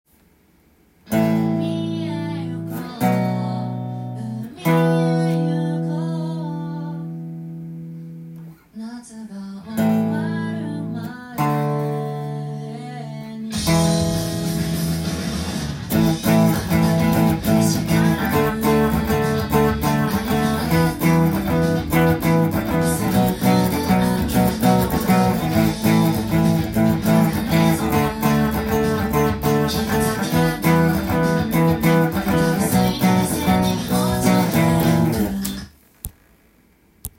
カンタンギターtab譜
音源にあわせて譜面通り弾いてみました
keyがGになります。